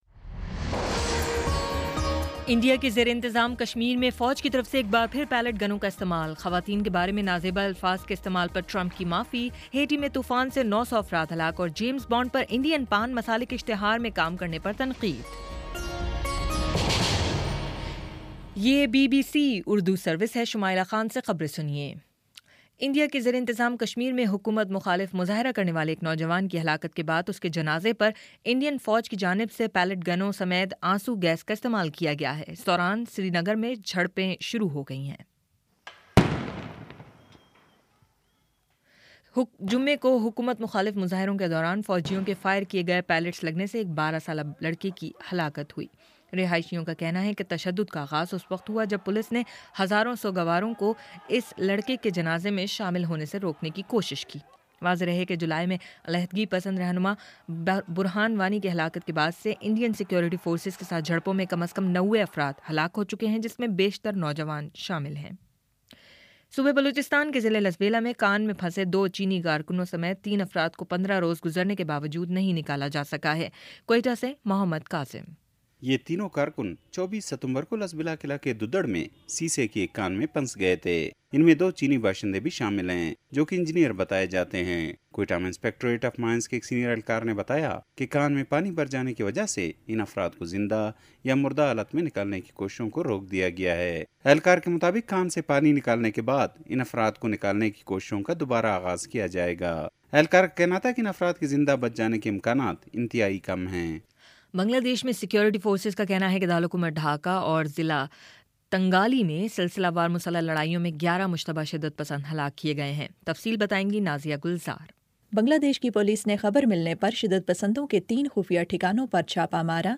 اکتوبر 08 : شام چھ بجے کا نیوز بُلیٹن